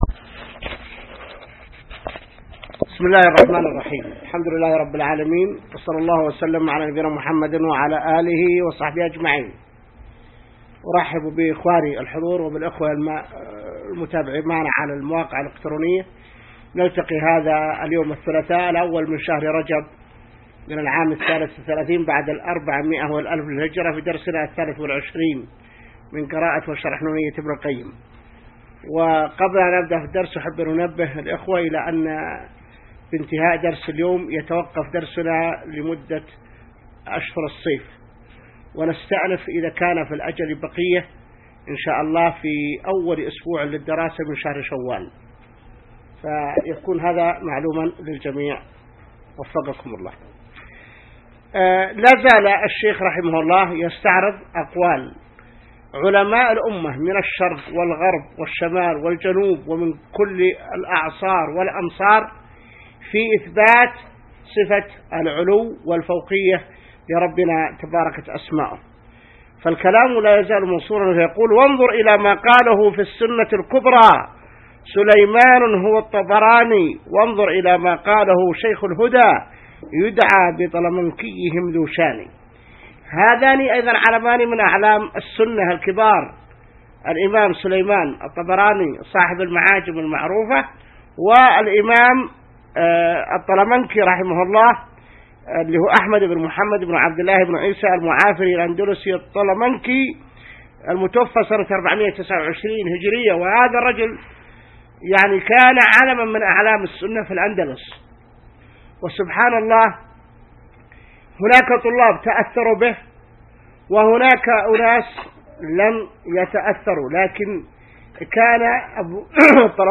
الدرس 23 من شرح نونية ابن القيم | موقع المسلم